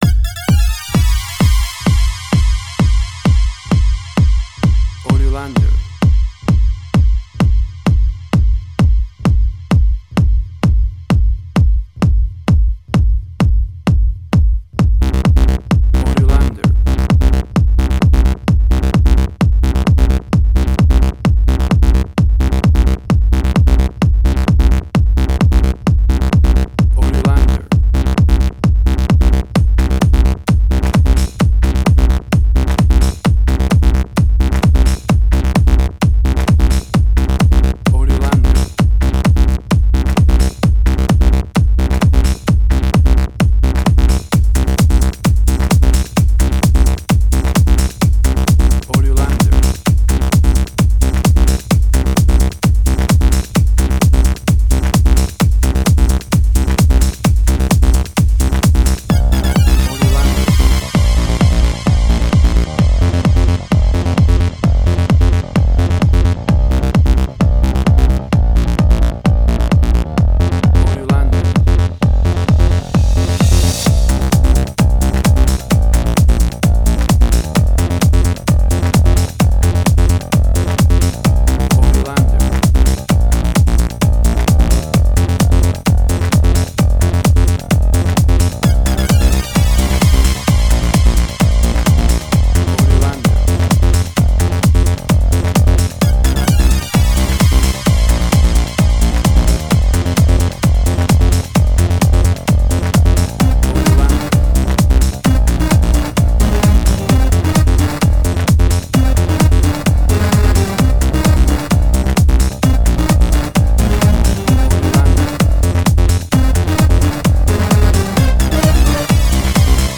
House.
Tempo (BPM): 129